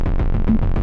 描述：循环的节奏来自Moog rogue和Phototheremin的即兴创作
Tag: 即兴 循环 穆格 光特雷门 样品 空间 老虎